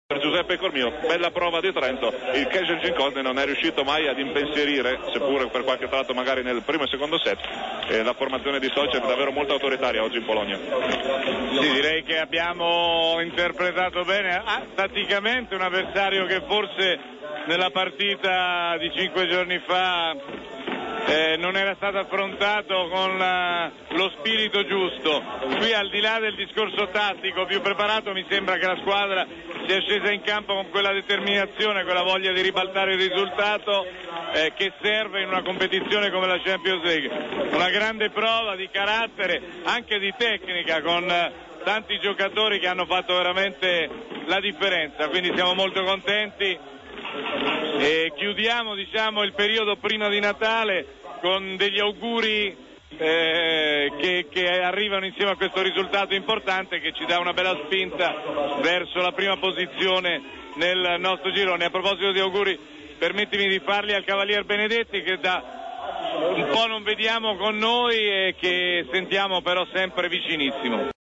Interviste mp3